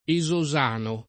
[ e @ o @# no ]